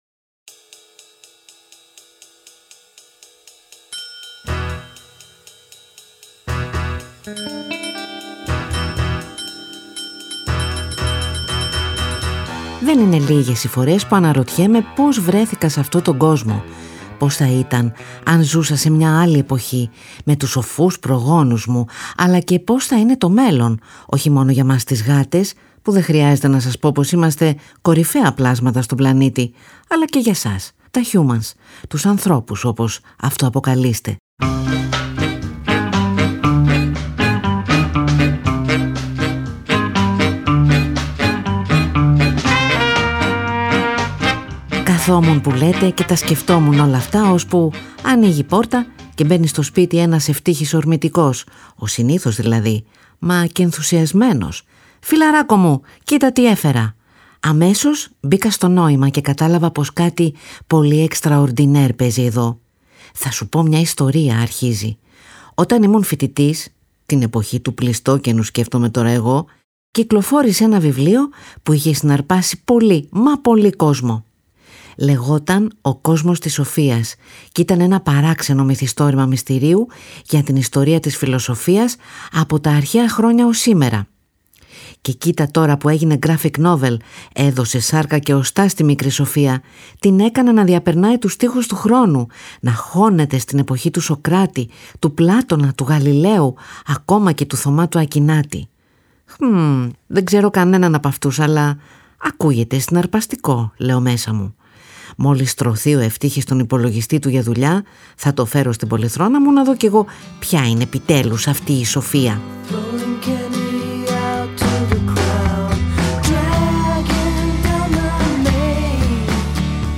Στο πικάπ γυρίζουν οι Edie Brickell and the New Bohemians, Al Stewart, Bob Marley and the Wailers, Tinariwen, Bruce Springsteen, Rolling Stones και Elliott Smith, μεταξύ άλλων, γιατί κι αυτούς τους απασχόλησαν τα ίδια ερωτήματα.
Ο Κατ μαθαίνει από τον Ευτύχη και μάς αφηγείται κι από μια ιστορία που διάβασε και πάντα την συνδέει με ένα αγαπημένο τραγούδι.